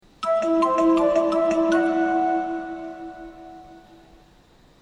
操作方法は、車掌が携帯しているワイヤレスマイクのスイッチを押すことによって一回のみ流れます。
池袋線 池袋線 上り 発車メロディ 池袋線 下り 発車メロディ 新宿線 新宿線 上り 発車メロディ 新宿線 下り 発車メロディ 武蔵境駅 西武ホーム 発車メロディ